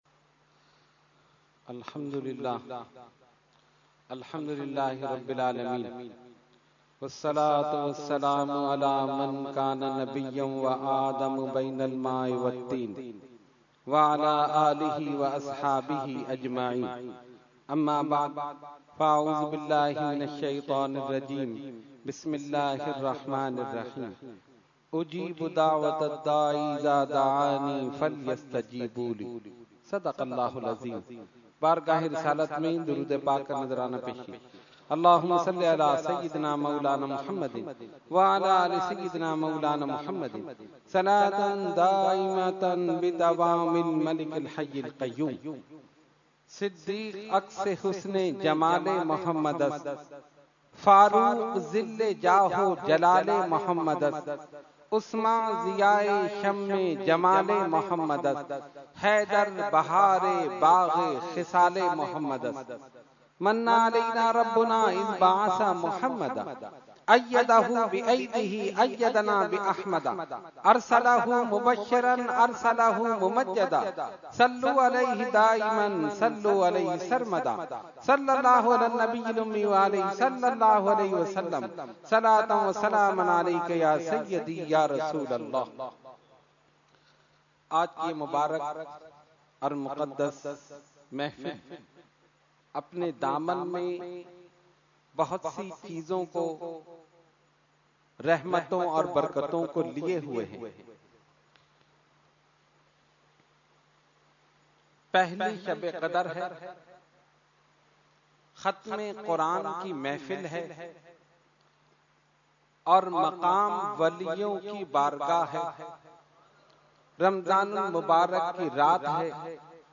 Category : Speech | Language : UrduEvent : Khatmul Quran 2017